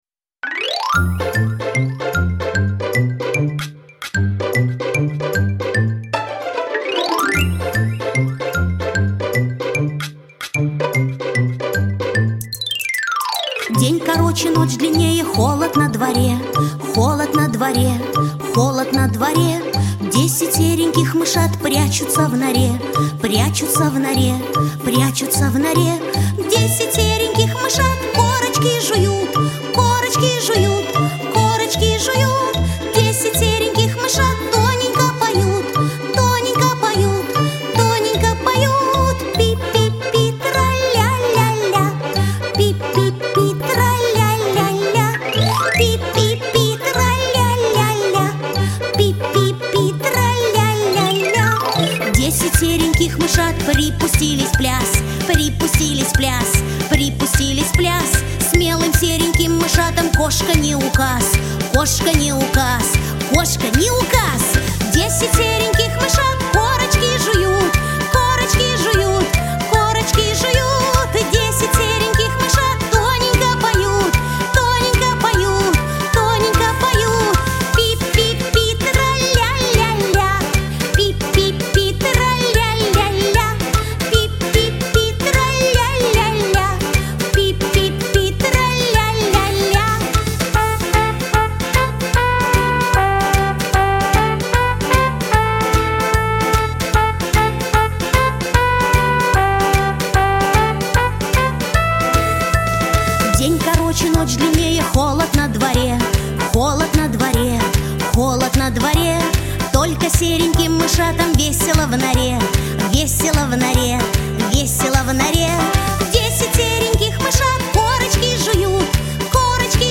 Песенки про зиму